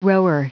Prononciation du mot rower en anglais (fichier audio)
Prononciation du mot : rower